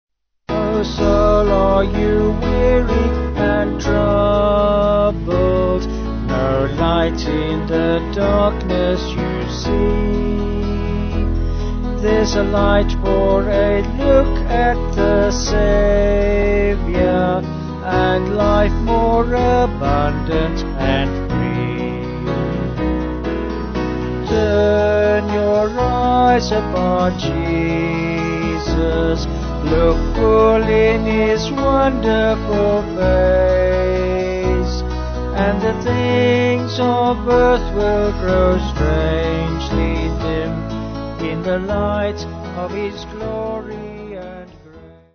Vocals & Band